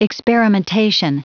Prononciation du mot experimentation en anglais (fichier audio)
Prononciation du mot : experimentation